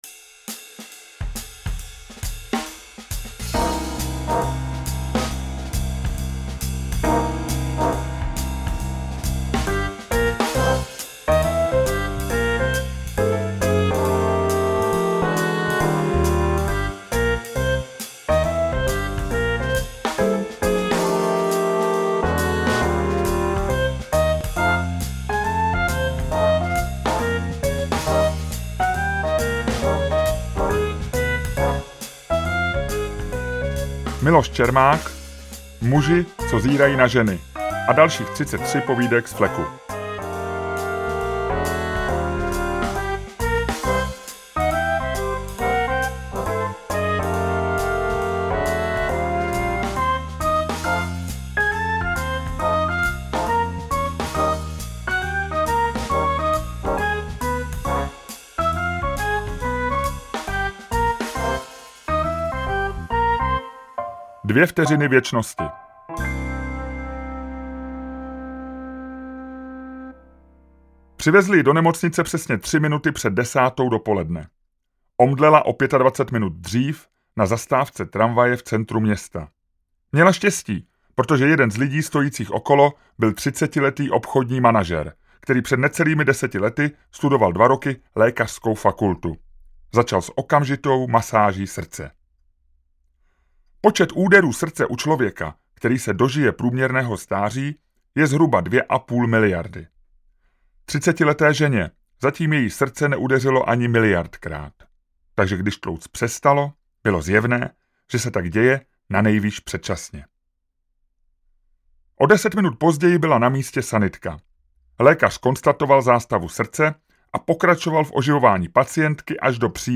Interpret:  Miloš Čermák
Čtyřiatřicet vtipných absurdních a hravých povídek „ze života“, jejichž hrdinové se ve své snaze o štěstí, sex, uznání a peníze občas vypraví i za hranice obvyklých jevů, vypráví sám autor – známý český novinář a komentátor.